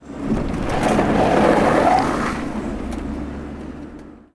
Frenada fuerte de un coche 2
frenar
Sonidos: Transportes